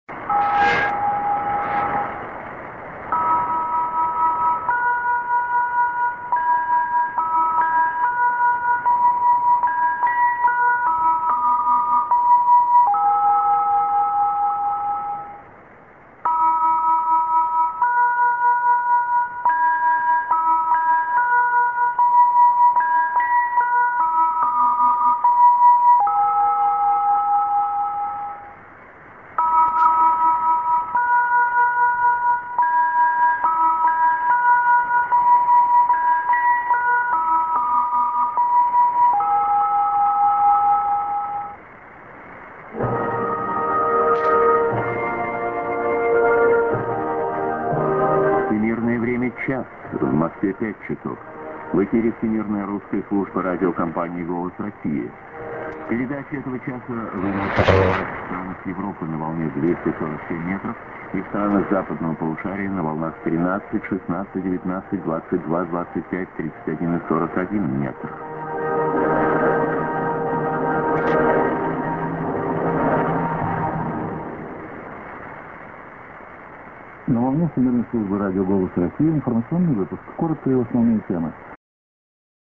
St. IS->ID(man) New Freq.